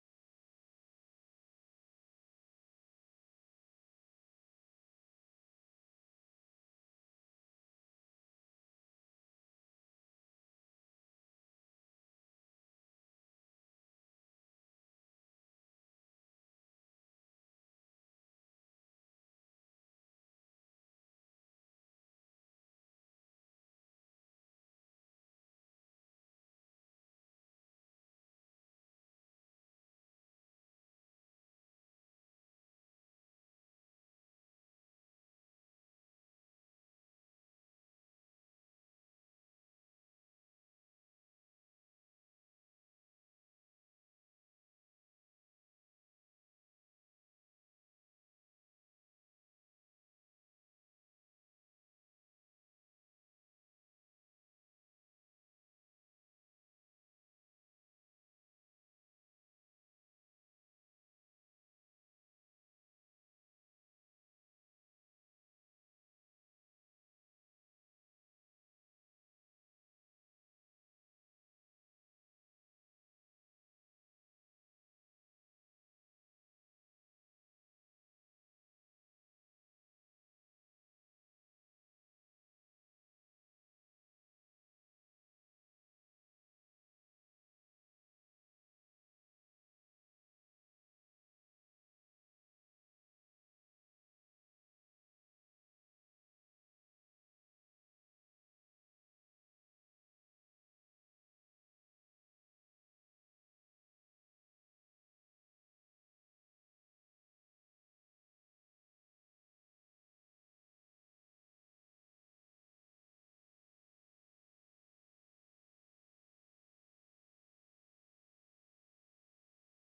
Засідання Комітету від 7 січня 2026 року